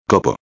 04/04/2011 copo floco •\ [co·po] \• •\ Substantivo \• •\ Masculino \• Significado: Pequeña formación de nieve cristalizada que cae de las nubes.
copo.mp3